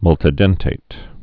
(mŭltĭ-dĕntāt)